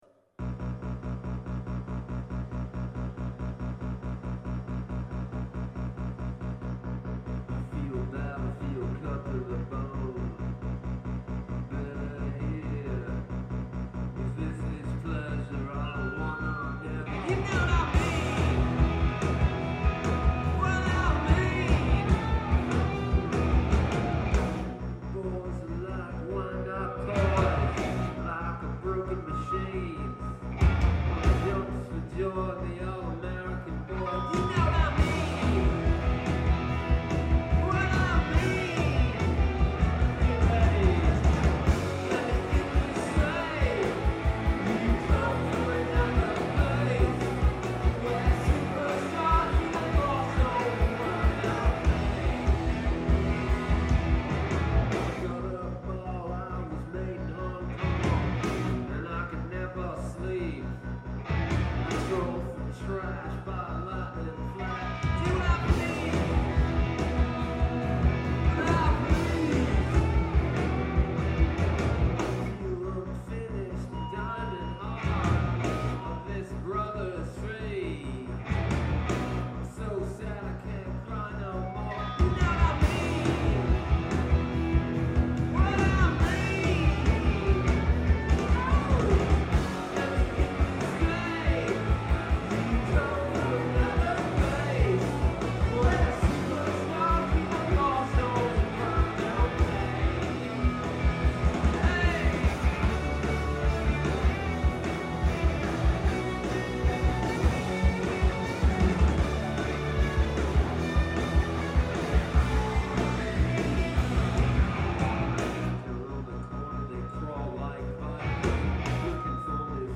drums
keyboards
bass
guitar and vocals